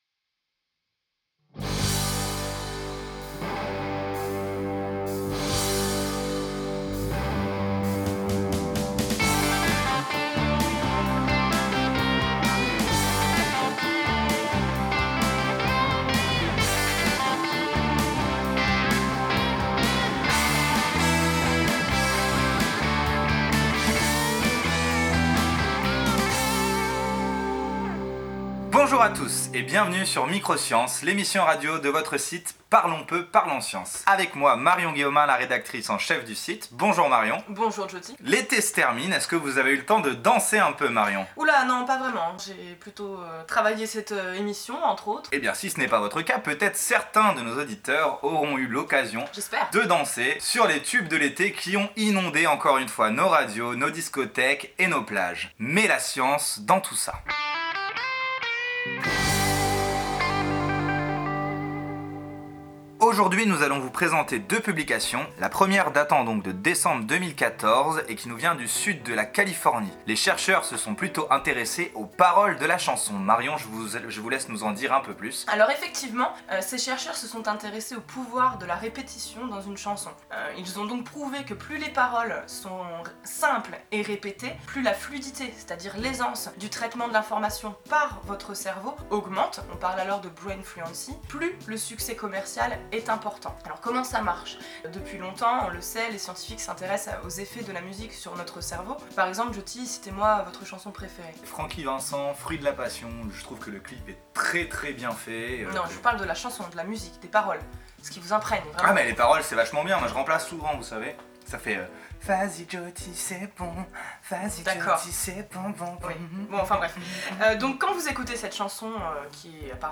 Notre première émission de radio s’intéresse aux tubes !
Générique
Musique d'ambiance